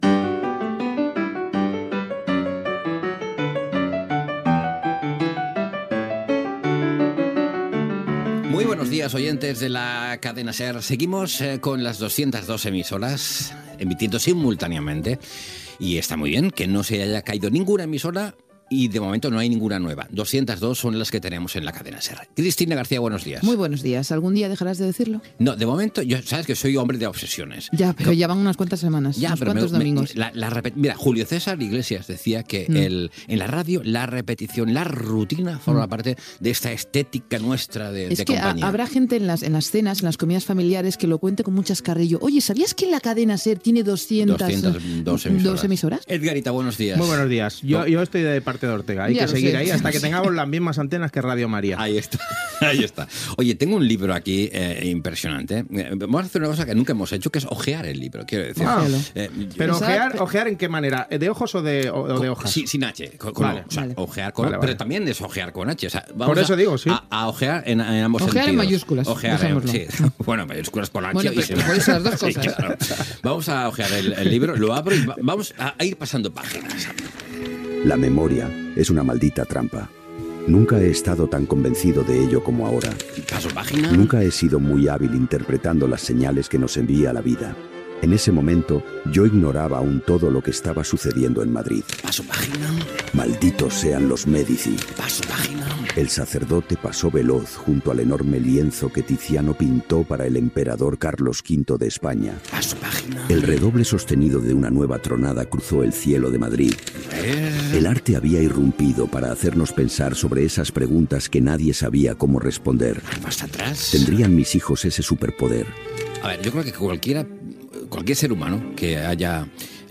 El nombre d'emissores de la Cadena SER, diàleg entre els presentadors, breu ficció sonora sobre la lectura d'un llibre, entrevista a l'escriptor Javier Sierra
Entreteniment
FM